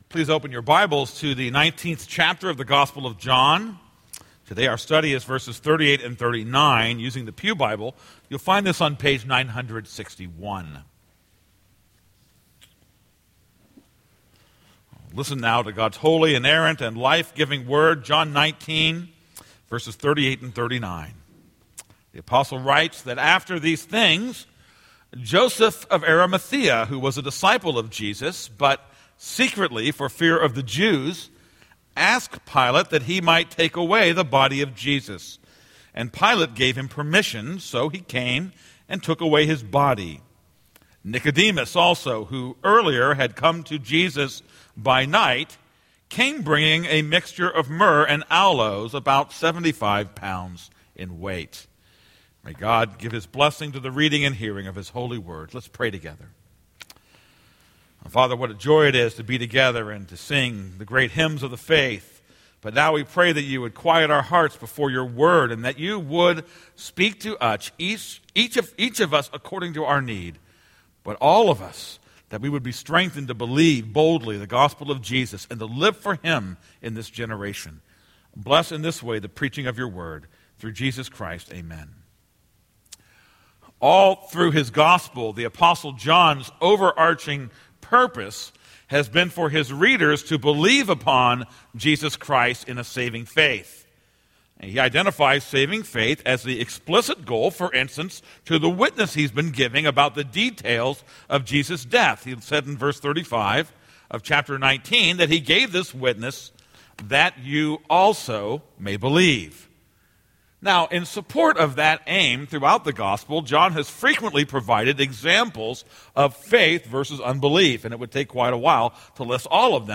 This is a sermon on John 19:38-39.